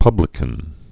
(pŭblĭ-kən)